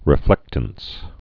(rĭ-flĕktəns)